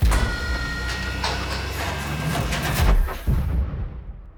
push.wav